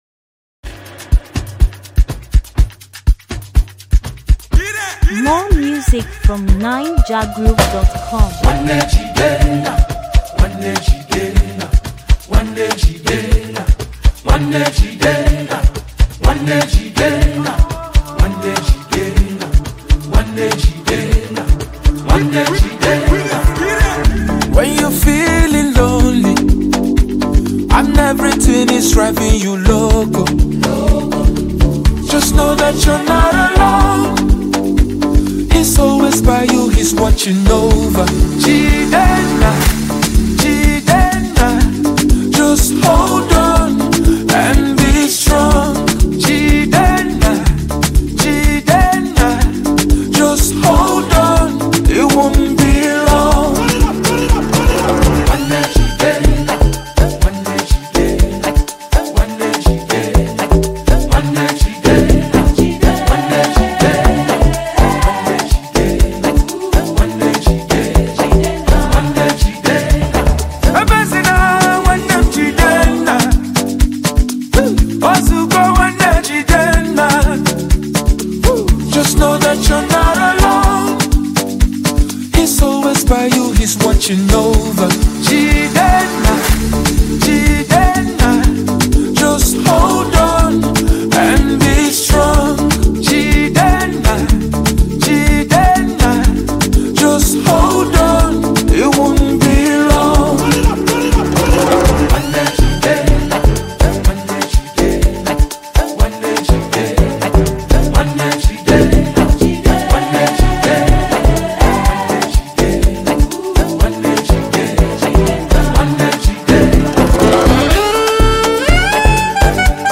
warm, textured record